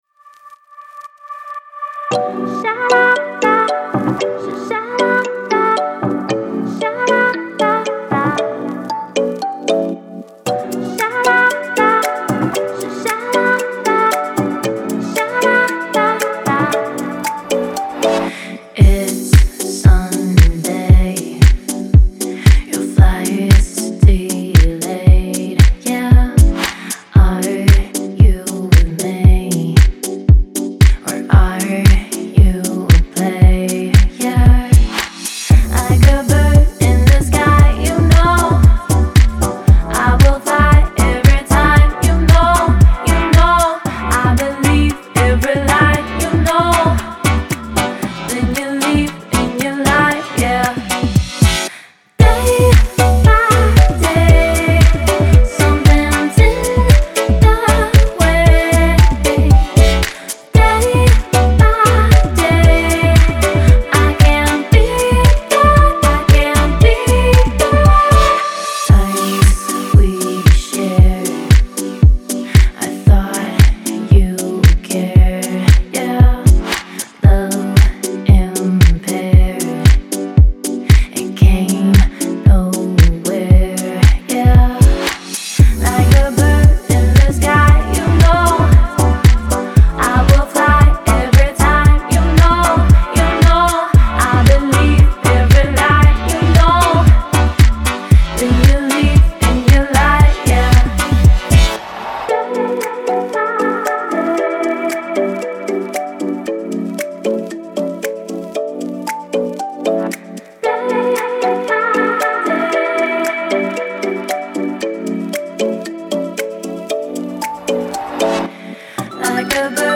это яркий трек в жанре поп с элементами электронной музыки